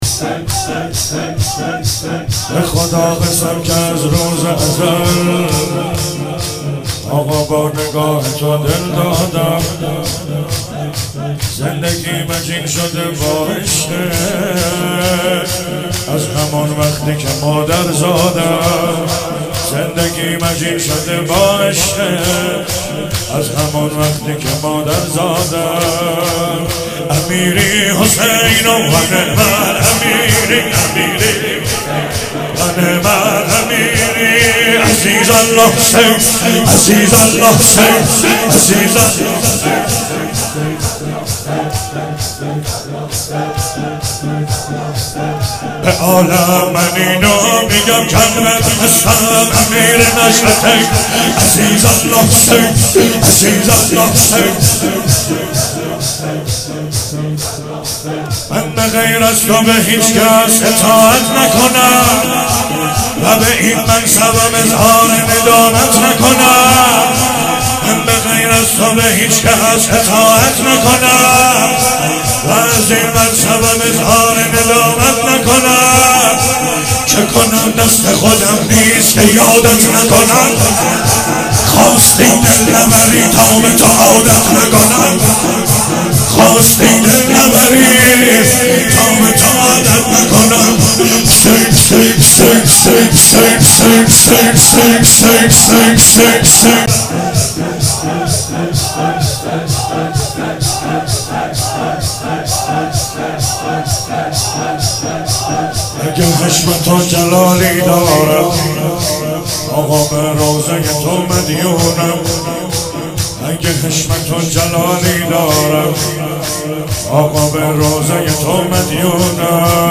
شب هشتم محرم 96 - شور - به خدا قسم که از روز ازل